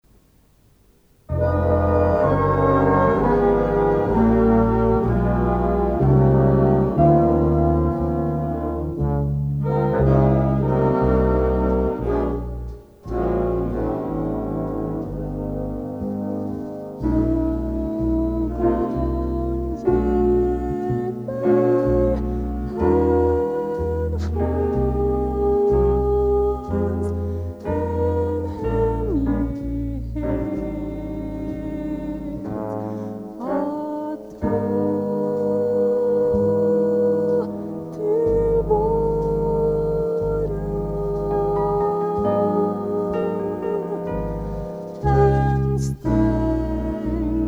A ballad for big band with solo flygelhorn or vocal.